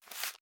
04_书店内_移动书本.wav